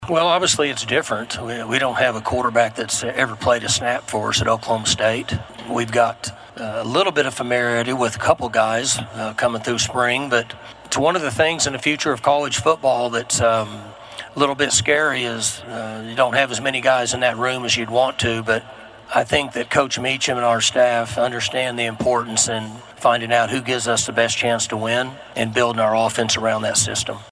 Oklahoma State head football coach Mike Gundy took the podium for Big 12 Media Days yesterday in Frisco, Texas.
Gundy (1) on QBs 7-10.mp3